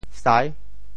“柹”字用潮州话怎么说？
sai6.mp3